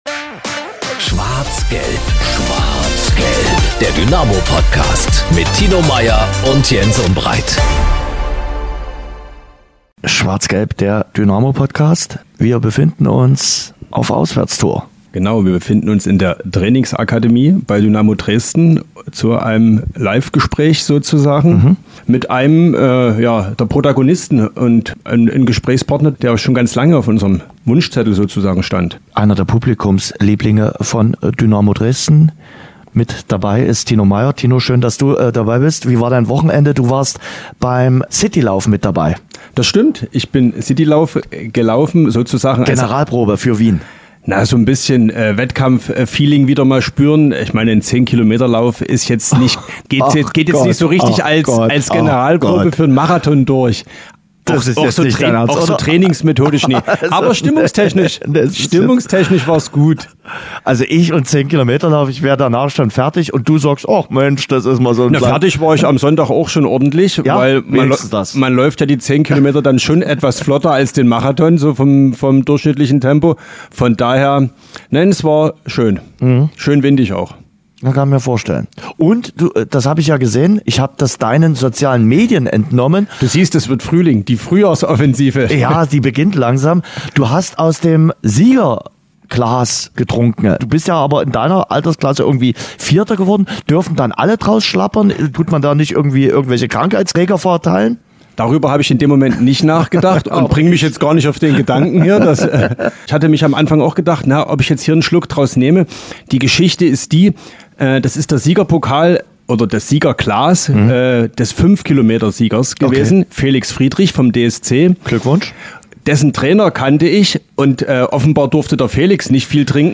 im Interview ~ SCHWARZ GELB - Der Dynamo-Podcast